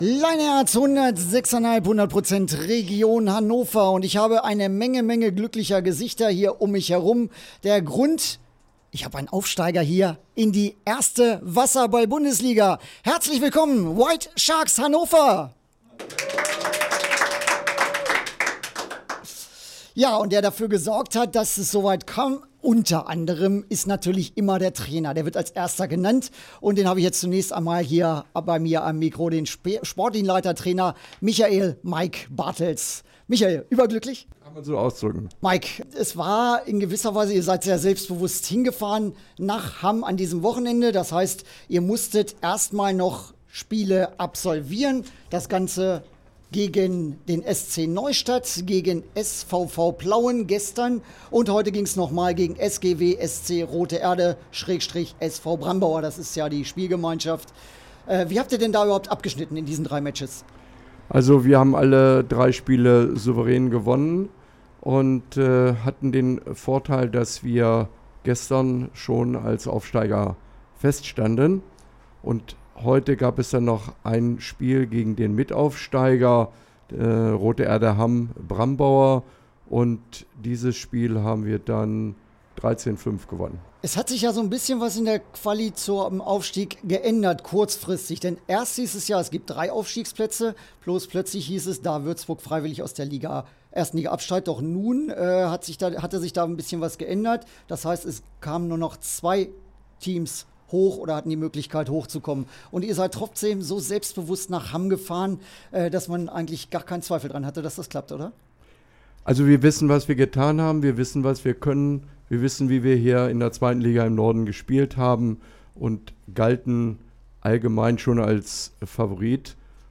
Liveinterview